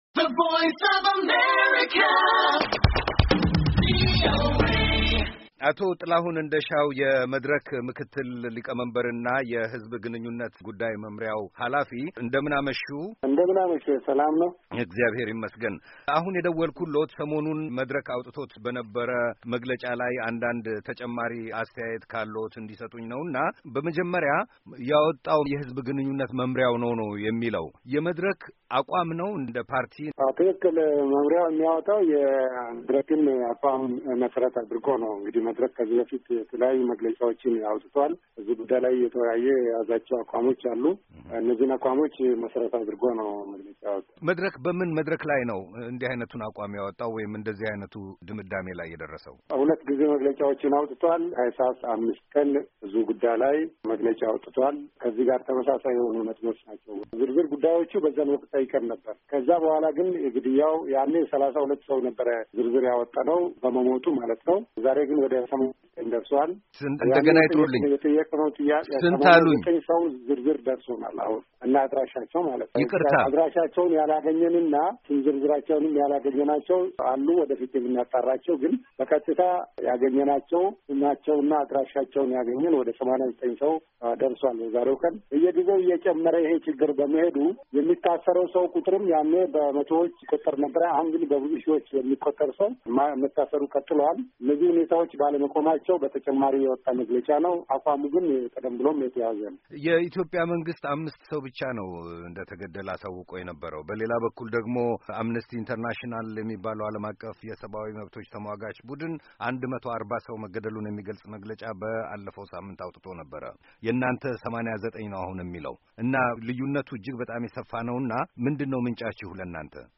ሙሉ ቃለ-ምልልስ